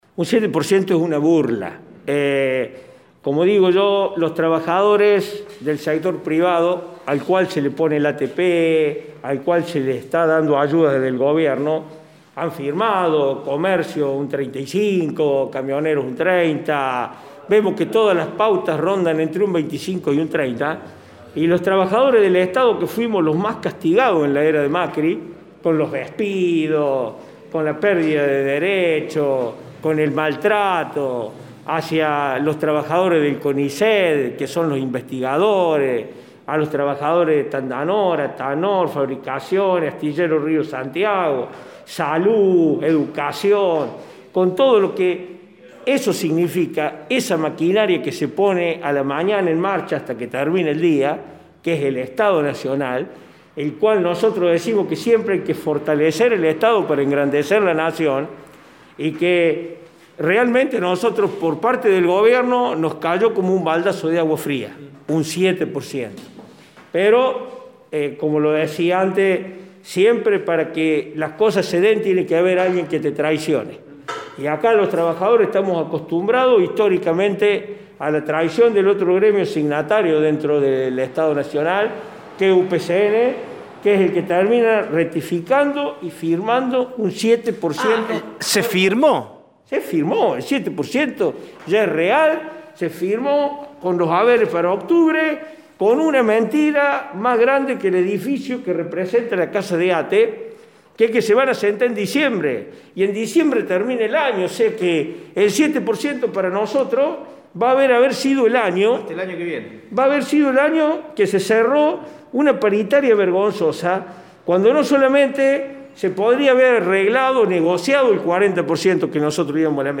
En Villa María, integrantes del gremio ATE y la CTA local, realizaron ayer una conferencia de prensa para plantear su disconformidad con el aumento otorgado.